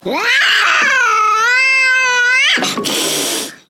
Soundscape Overhaul / gamedata / sounds / monsters / cat / c_die_2.ogg
c_die_2.ogg